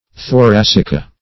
Search Result for " thoracica" : The Collaborative International Dictionary of English v.0.48: Thoracica \Tho*rac"i*ca\, n. pl.